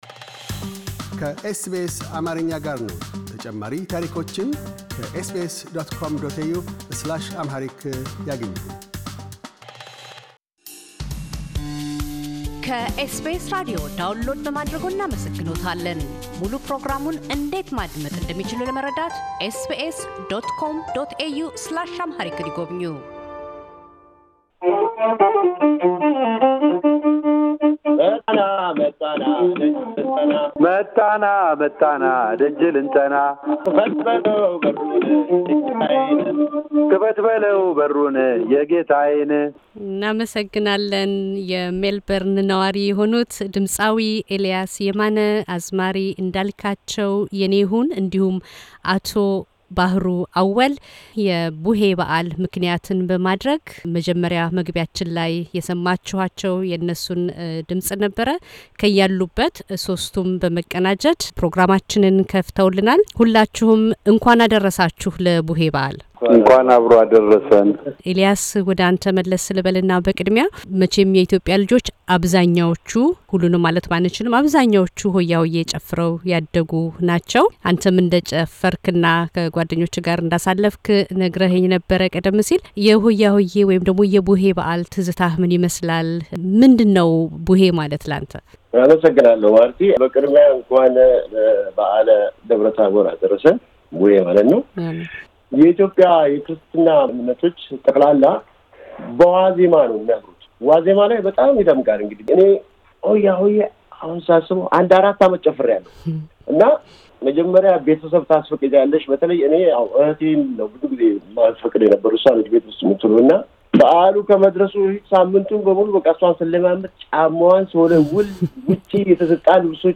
የኪነ ጥበባዊ ስጦታቸውን ተጠቅመው የልጅነት ትውስታቸውን በድምጻቸው በማንጎራጉር በዓሉን በአል አስመስለዋል ።